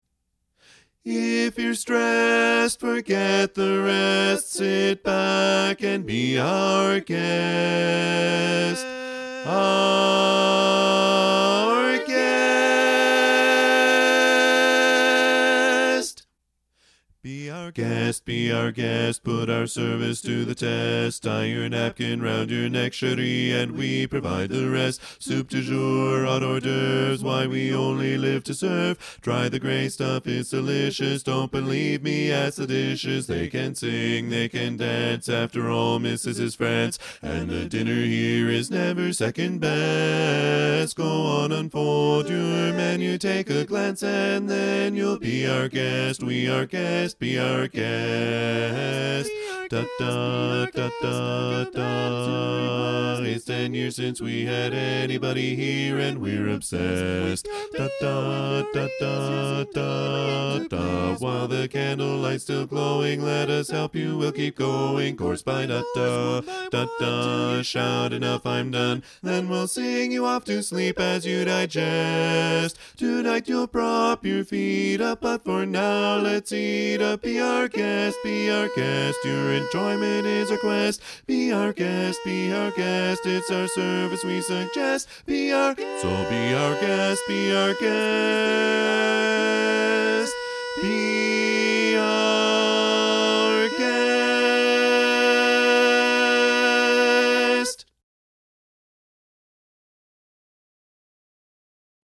Up-tempo
B♭ Major
Bass